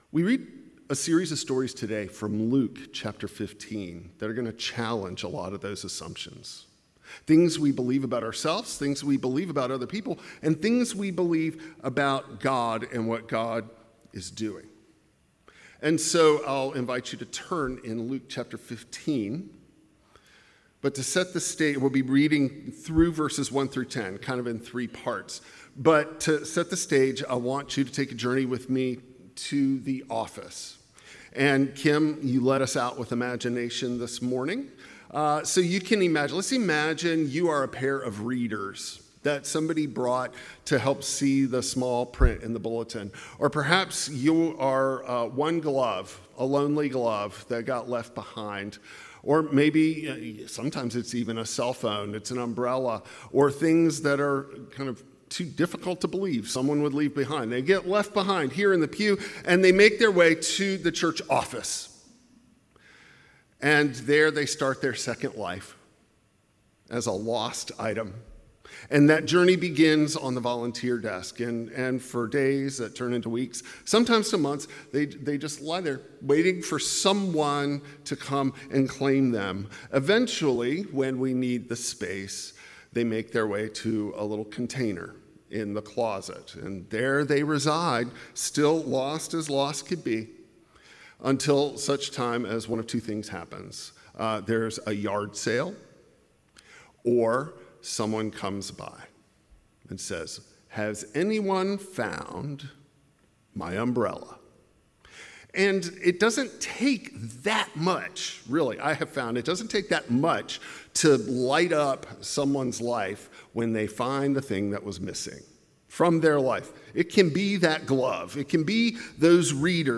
A sermon on Luke 15:1–10 about God’s relentless grace, seeking the lost, and celebrating foundness in community.